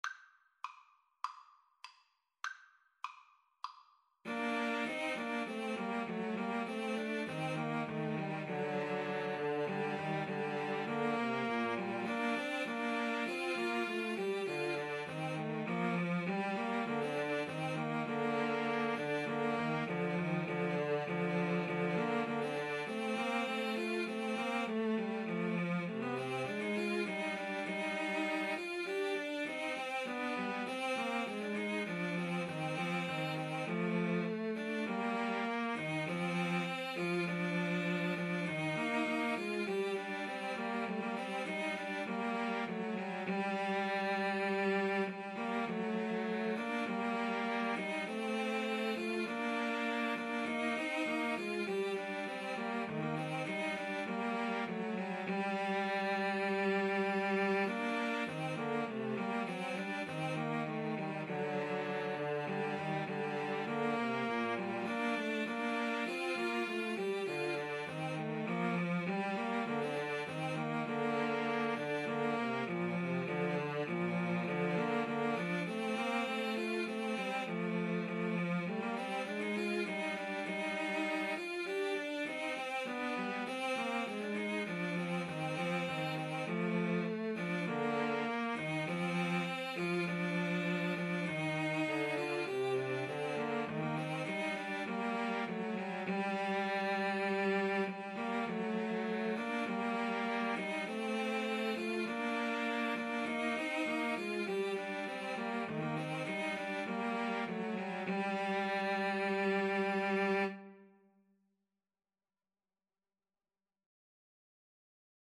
Classical (View more Classical Cello Trio Music)